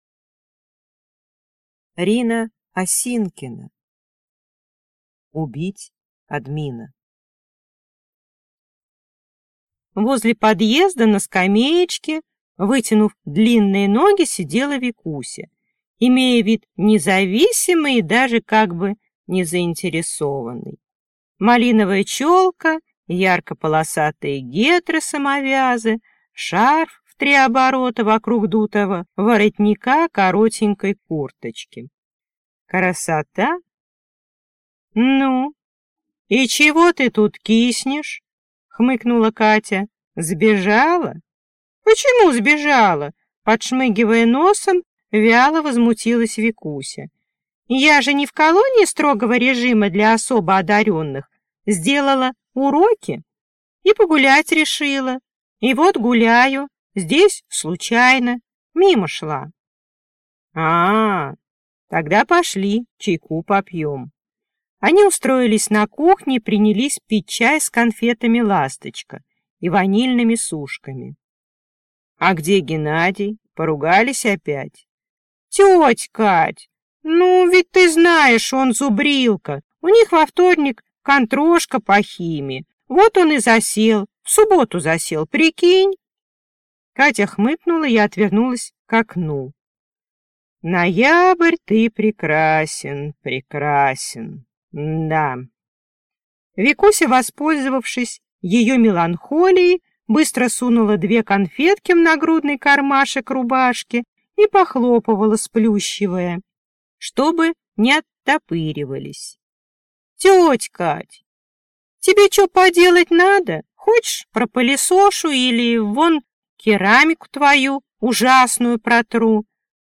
Аудиокнига Убить админа | Библиотека аудиокниг